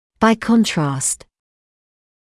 [baɪ ‘kɔntrɑːst][бай ‘контраːст]наоборот; в противоположность